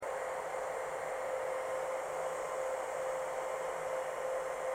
ambient_hiss.mp3